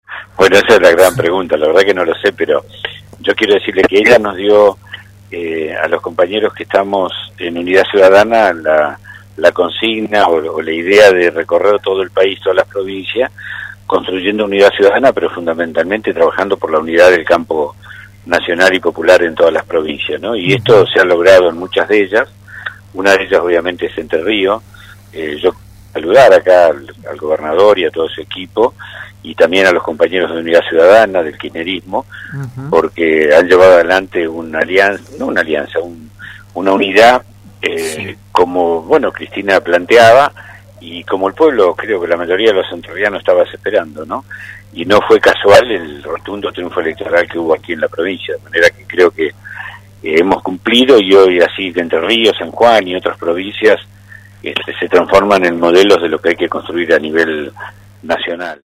El ex secretario general de la presidencia dialogó con Radio RD 99.1 en ocasión de la inauguración de un espacio de debate kirchnerista en Paraná, llamado Casa Patria: